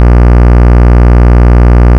Listen to how the timbre changes as you add more and more harmonics....